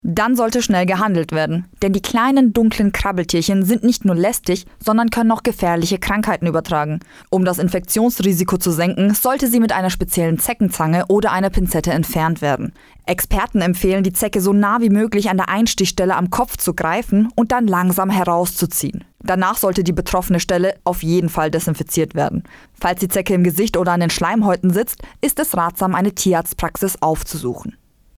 Interview: Zecken bei Tieren - so kann man sich schützen - PRIMATON